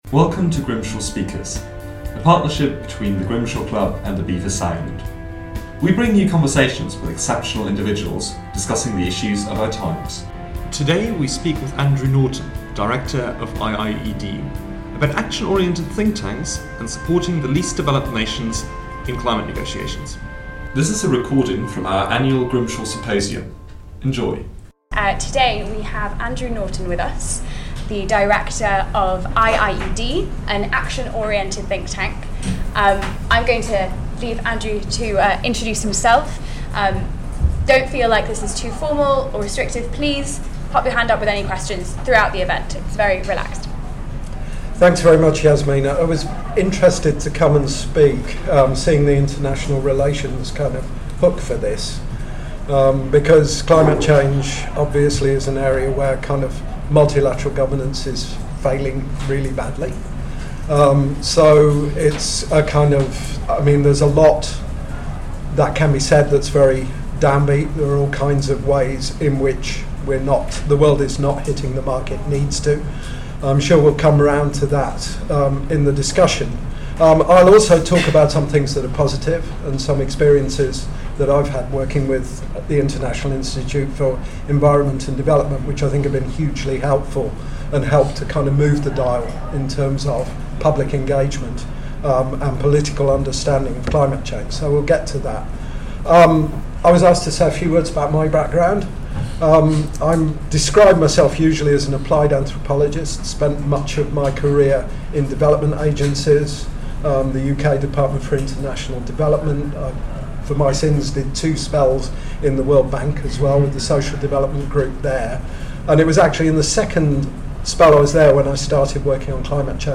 We bring you conversations with exceptional individuals, discussing the issues of our times.